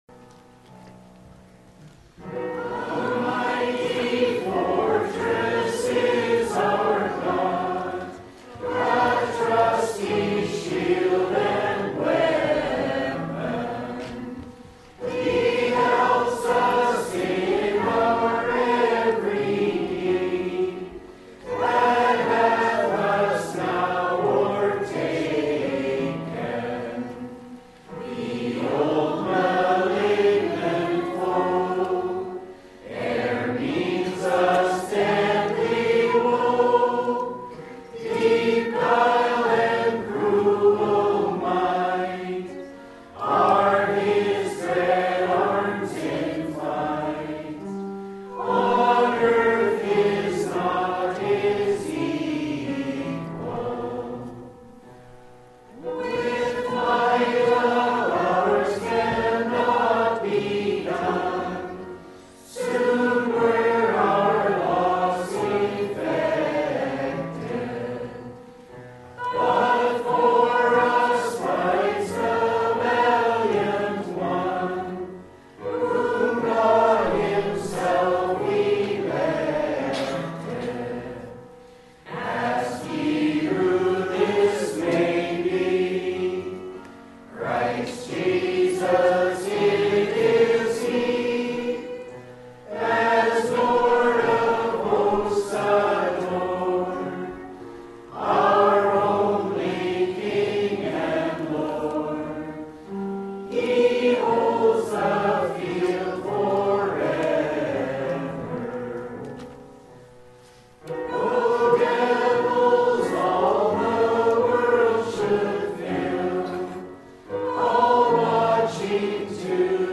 Sylvan Lake Apostolic Lutheran Church